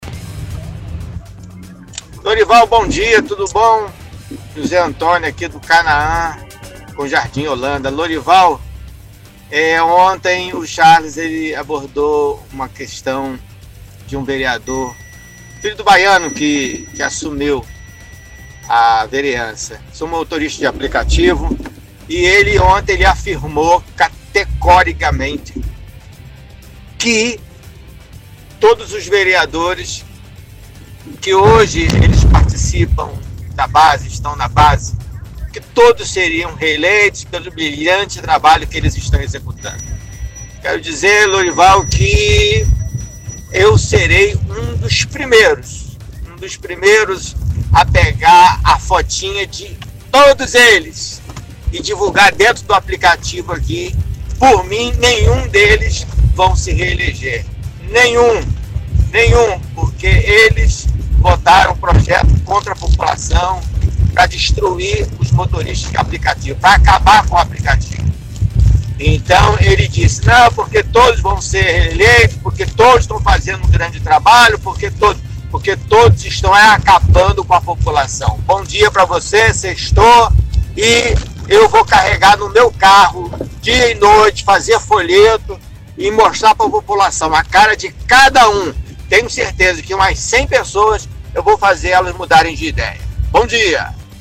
– Ouvinte do bairro Canaã fala sobre o vereador Jair Ferraz, diz que todos os vereadores que hoje participam da base do Odelmo, todos seriam reeleitos.